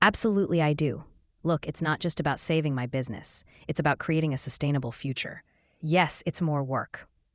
female_example.wav